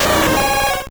Cri de Suicune dans Pokémon Or et Argent.